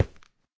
stone5.ogg